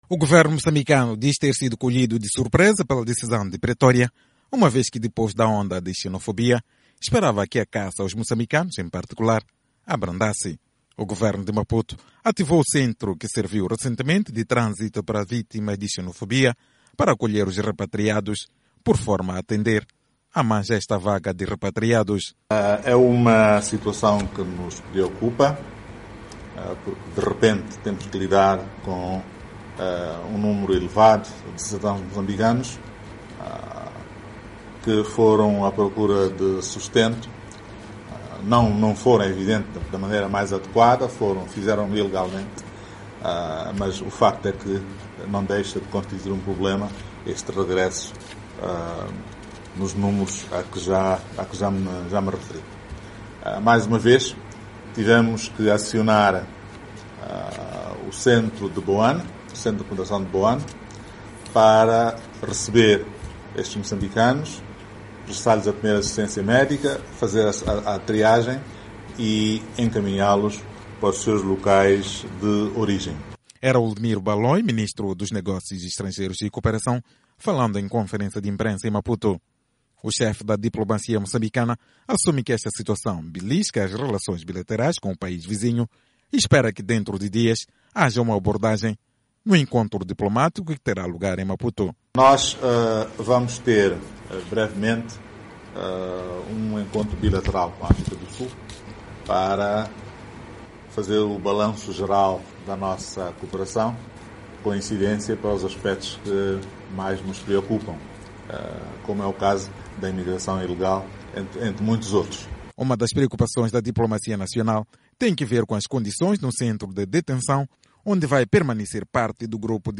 Ainda hoje, 420 serão deportados, o que cria uma situação de embaraço às autoridades de Maputo, como disse nesta sexta-feira, 15, em conferência de imprensa, o ministro dos Negócios Estrangeiros e da Cooperação.